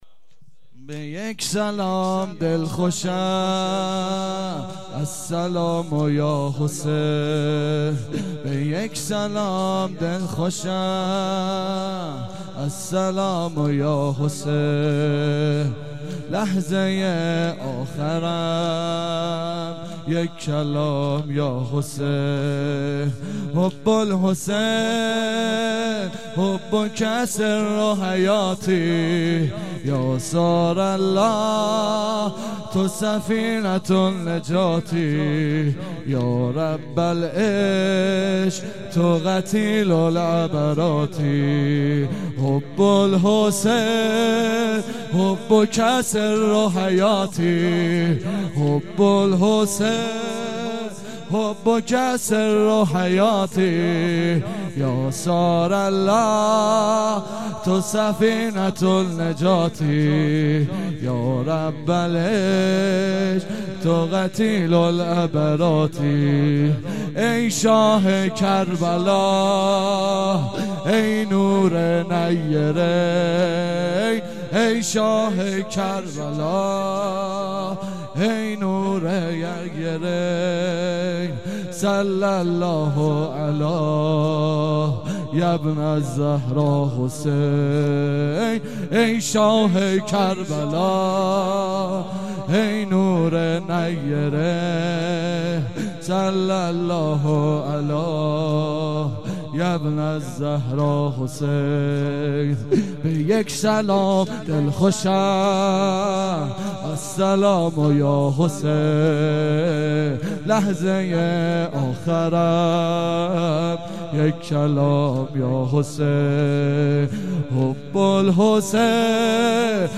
زمینه - به یک سلام دلخوشم
شهادت امام صادق
روضه